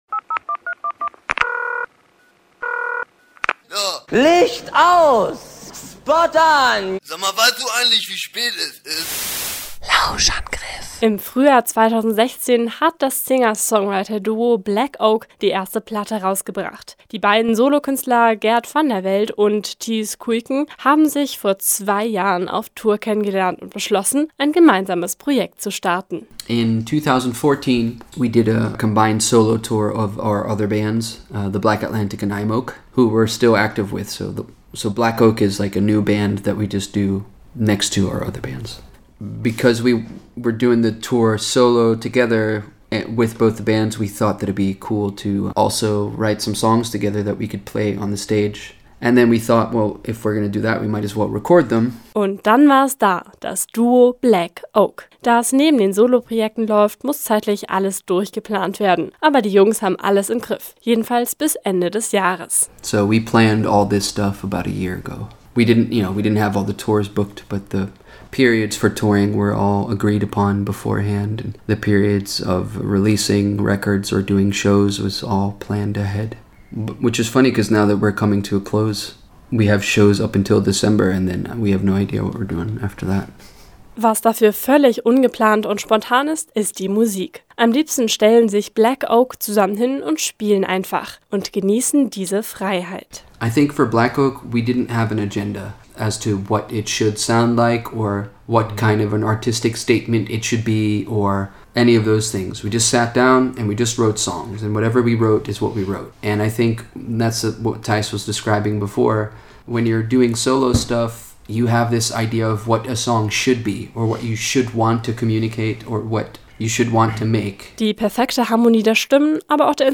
Bestechend klare und eindrucksvoll harmonische Musik macht das Duo “Black Oak”. Wie sich die beiden Singer/Songwriter kennengelernt haben und was sie tun, wenn sie parout keine Texte finden, haben sie uns im Interview erzählt.